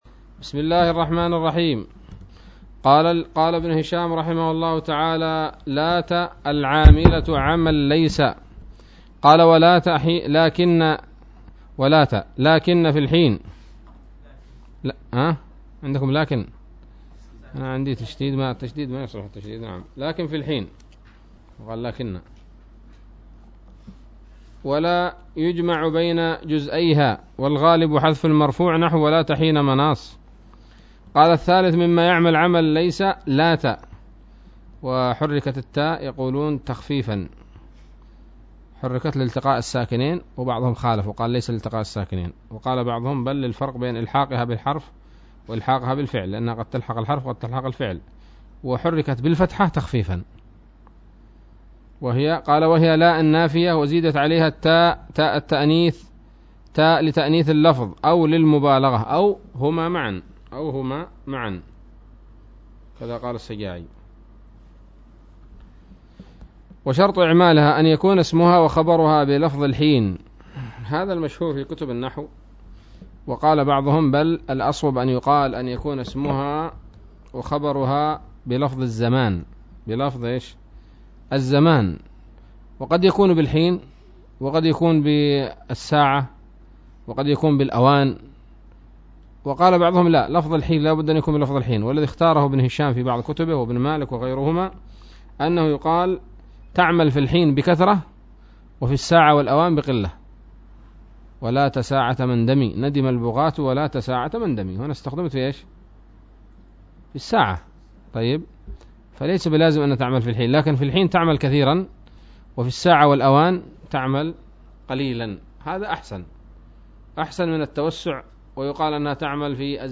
الدرس الحادي والستون من شرح قطر الندى وبل الصدى